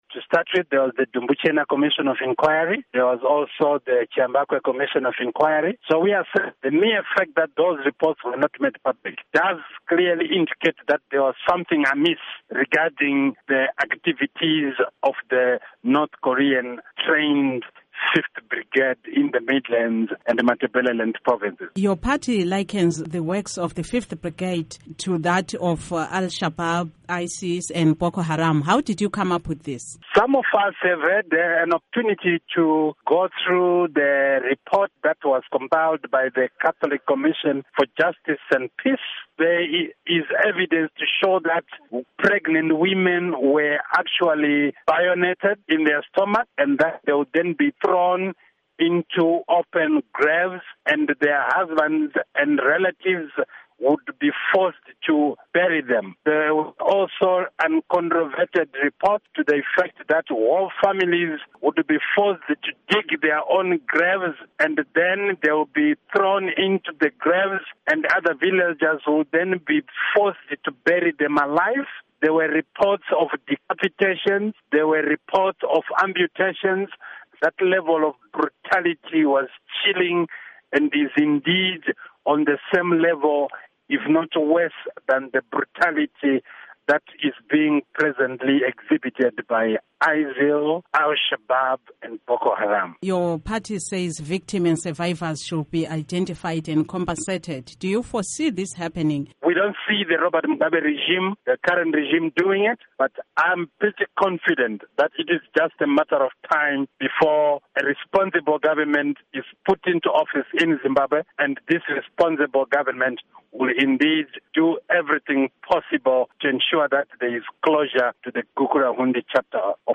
Interview with Obert Gutu